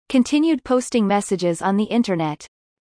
▶ 語末の子音D止まる音：文中ではほとんど聞こえない